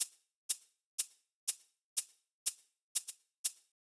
ORG Beat - Shaker.wav